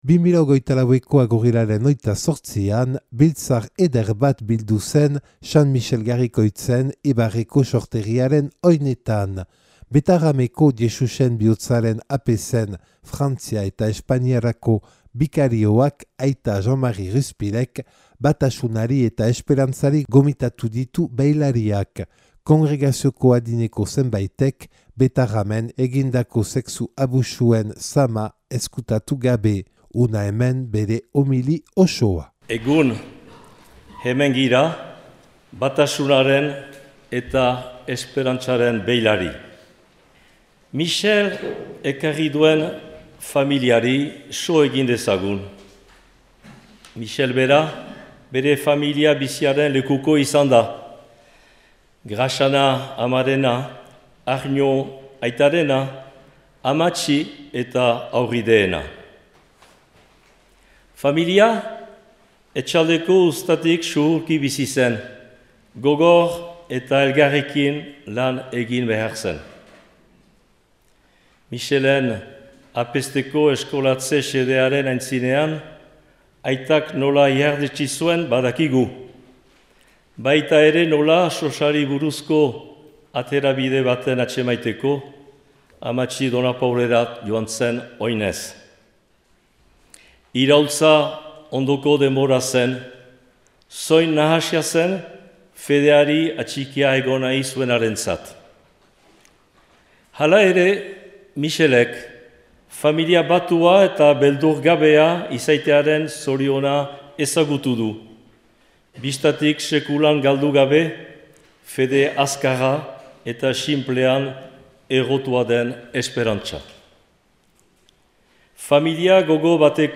Huna hemen bere homili osoa.